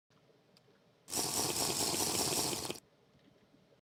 Звуки питья в Майнкрафт весьма специфичны и совсем не похожи на реальные звуки. Когда их слышишь в первый раз, создается ощущение что вы в спешке пьете очень горячий чай из блюдечка.
Оригинальный звук питья
Пьет-зелье-в-Майнкрафт-звук-для-монтажа-256-kbps.mp3